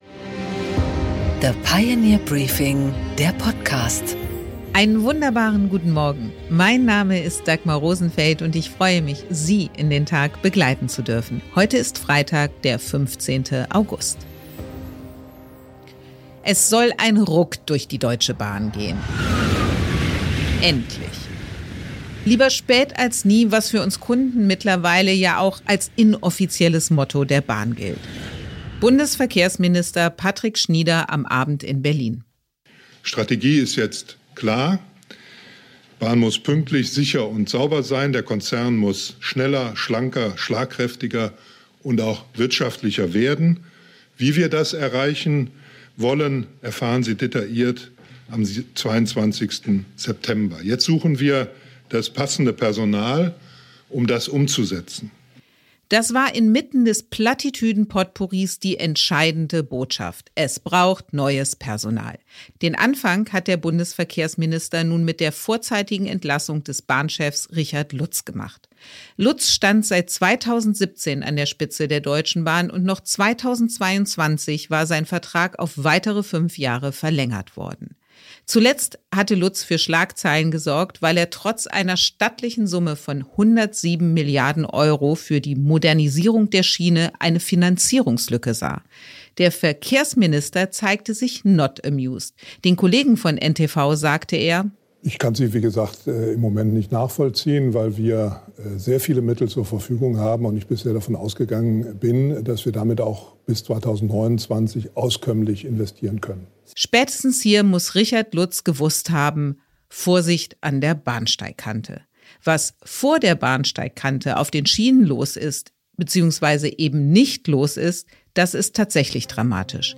Interview mit Norbert Röttgen